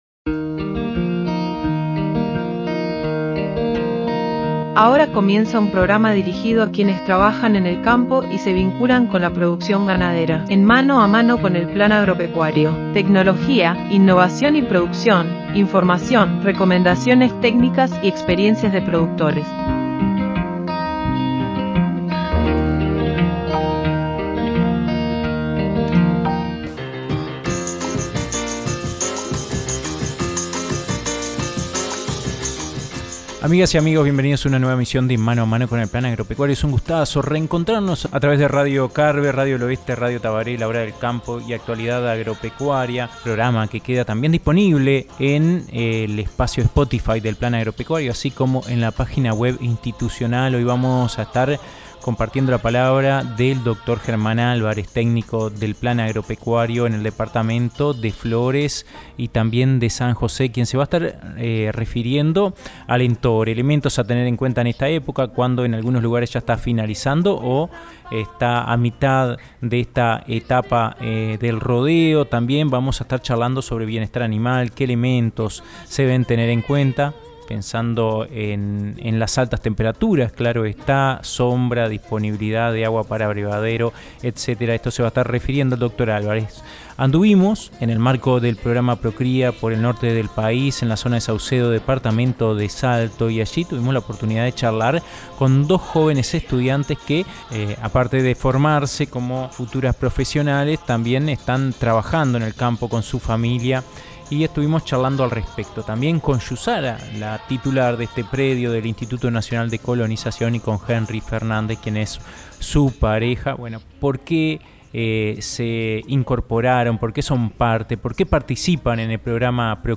Visitamos el Establecimiento "El miedo"